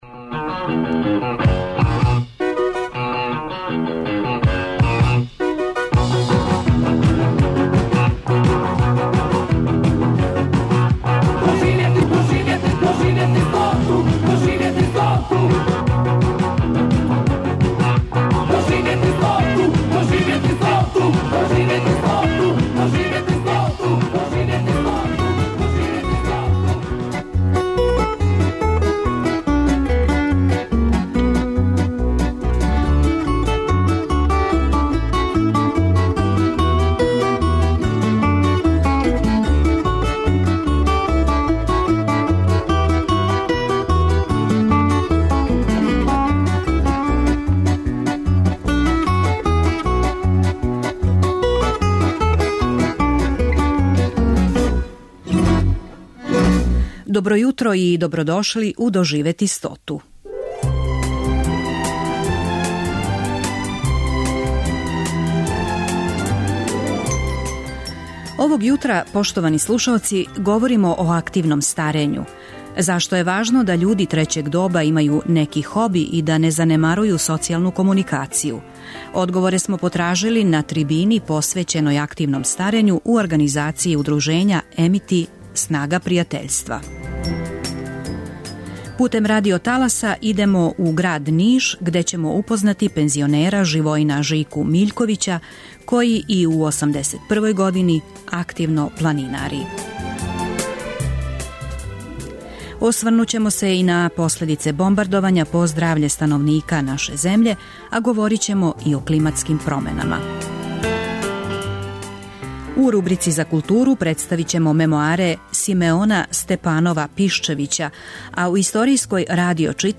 Одговоре смо потражили на трибини посвећеној активном старењу у организацији Удружења 'Amity - снага пријатељства'.
Емисија "Доживети стоту" Првог програма Радио Београда већ двадесет четири године доноси интервјуе и репортаже посвећене старијој популацији.